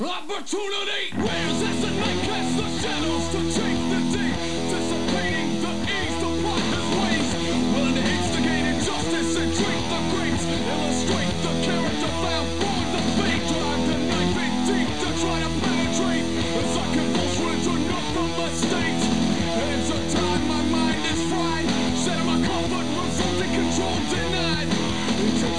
hardcore